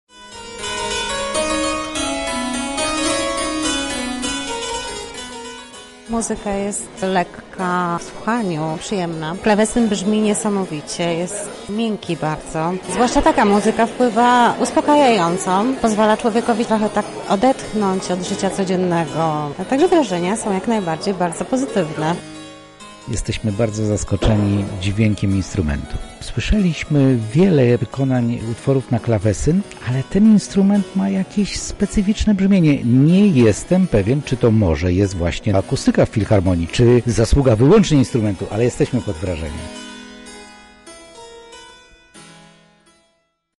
Uczestnicy zwrócili uwagę na nietypowe brzmienie klawesynu: